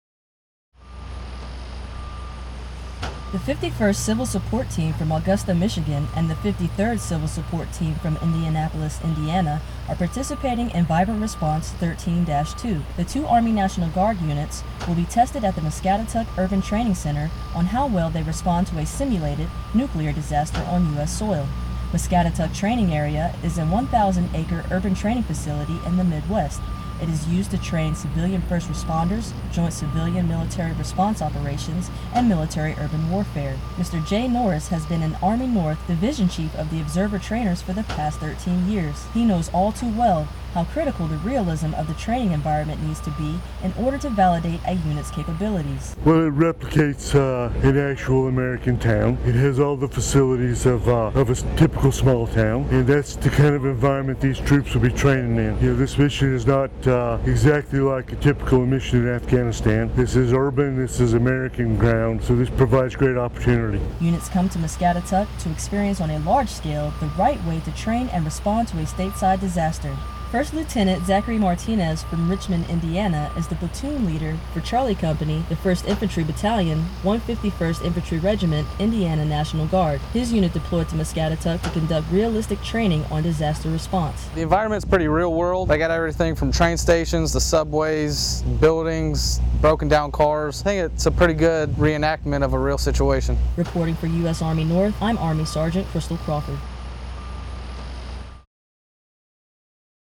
Decontamination reconnaissance training at Camp Atterbury during Vibrant Response.